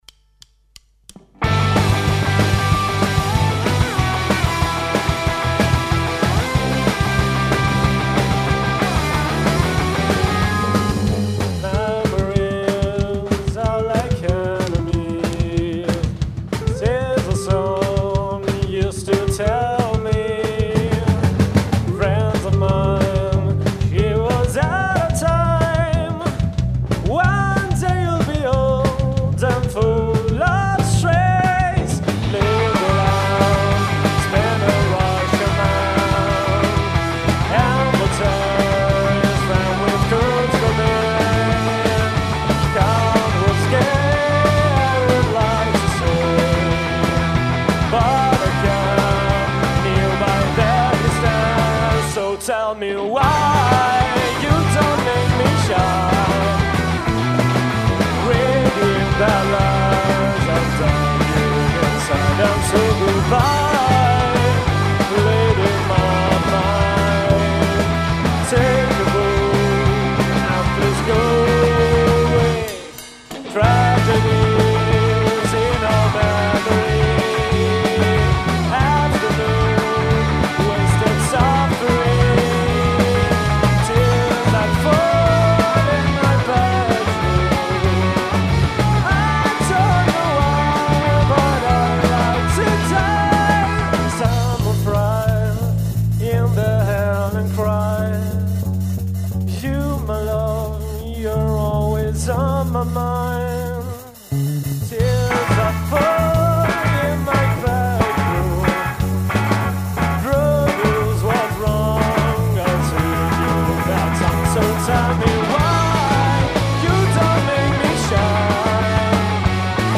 Luogo esecuzioneCorticella (Bo)